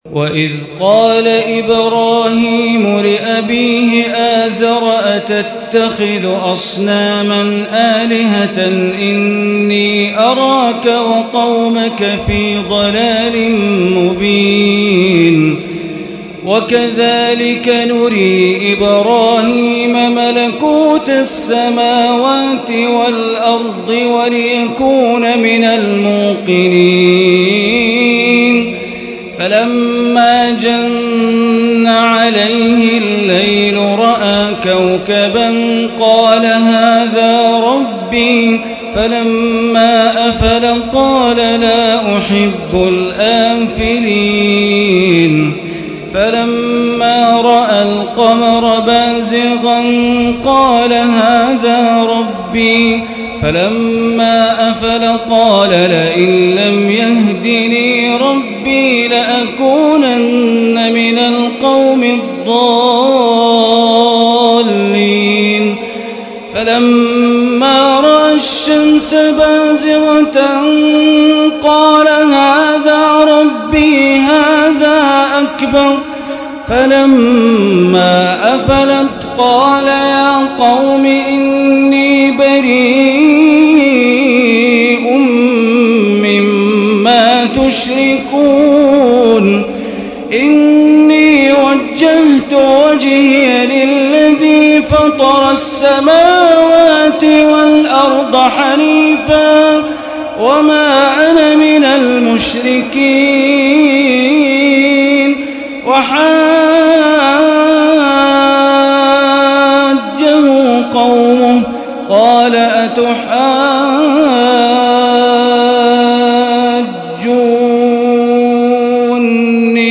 ليلة 07 من رمضان عام 1436 من سورة الأنعام الآية 74 إلى الآية 150 > تراويح ١٤٣٦ هـ > التراويح - تلاوات بندر بليلة